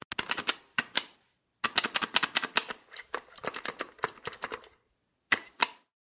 Звук клікання джойстика під час гри